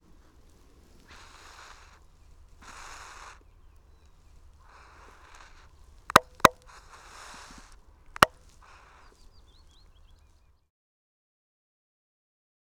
Eurasian Eagle-owl
Hissing and bill-snaps of a nestling about five weeks old.
41-Eurasian-Eagle-Owl-Hissing-And-Bill-Snaps-Of-Nestling.wav